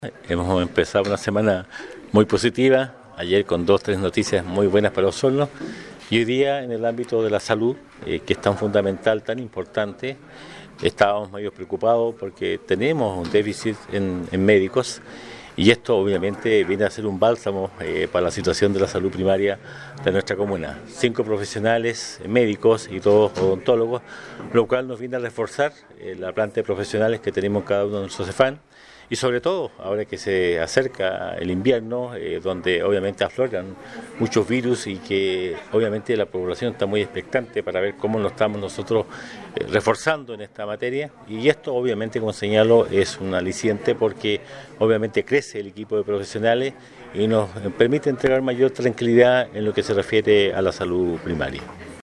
En un acto realizado en Sala de Sesiones, el municipio de Osorno dio la bienvenida a los cinco nuevos médicos y dos odontólogos que llegaron a trabajar a distintos Centros de Salud Familiar de la comuna, lo que es posible gracias al “Programa de Destinación y Formación”, del Ministerio de Salud.